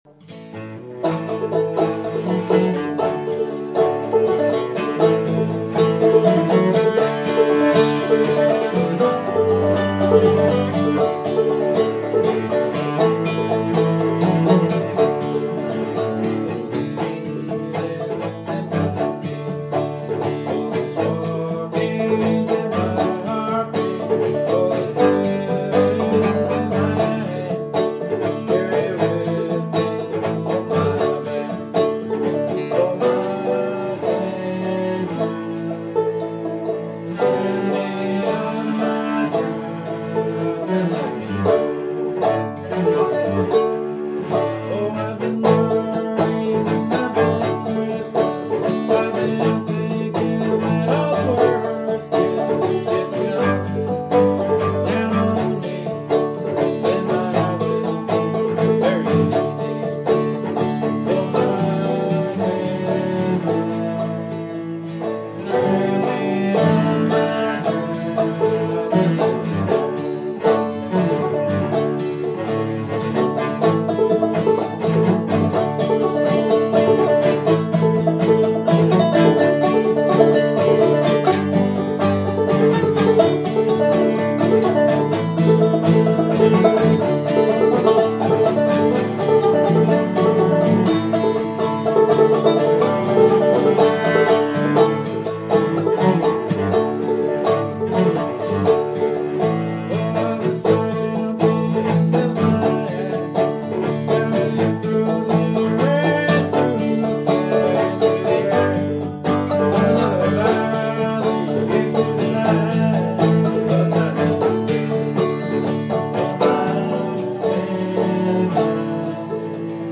banjo, vocals
cello, guitar
They are classically trained, but they play old-time music and write a lot of their own material.
These recordings were made an hour earlier, during a quick practice session in my living room.